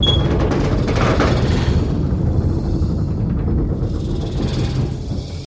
Metal Slide Creaking